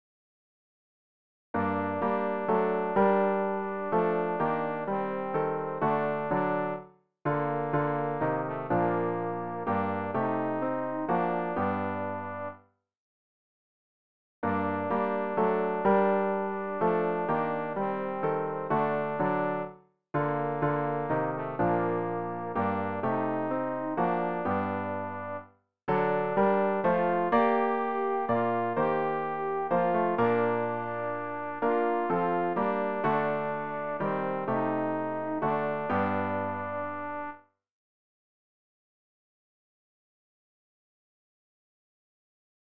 Übehilfen für das Erlernen von Liedern